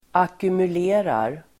Ladda ner uttalet
Uttal: [akumul'e:rar]